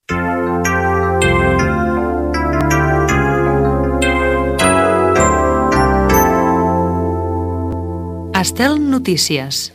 Careta del programa
FM